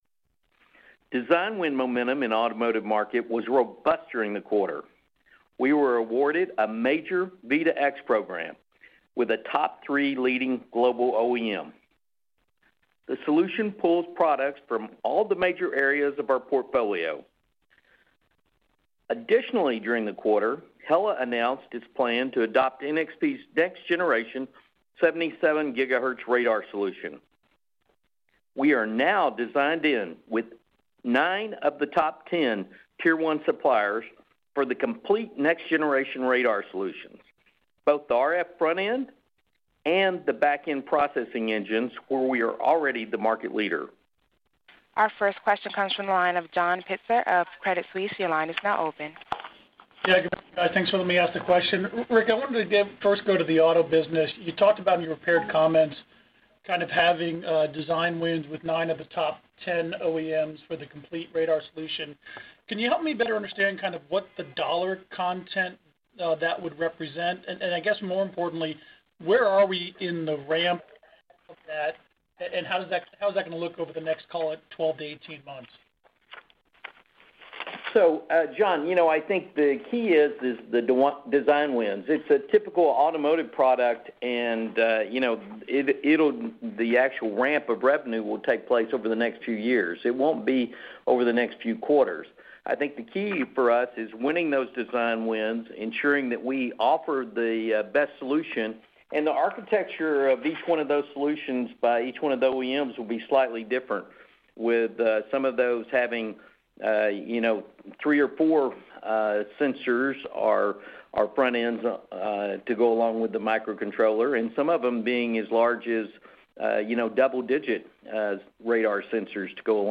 This audio contains his statement and responses to a couple of follow-up questions from analysts.